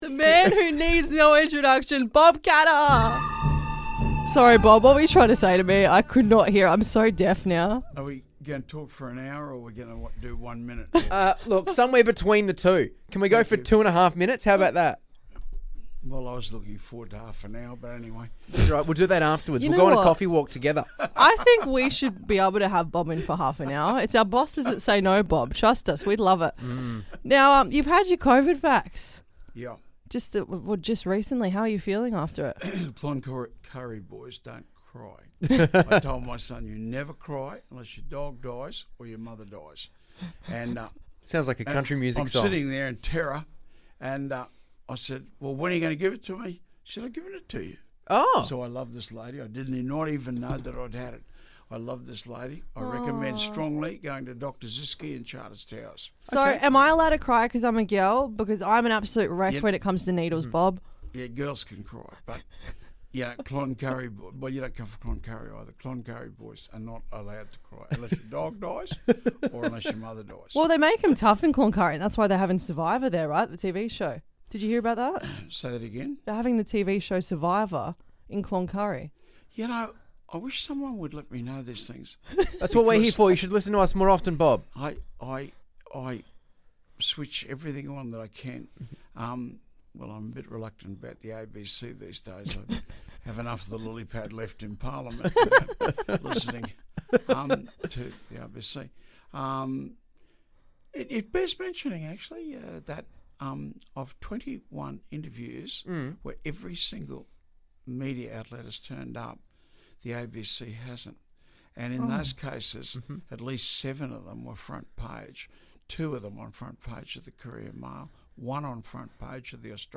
caught up with Bob Katter in the breakie show.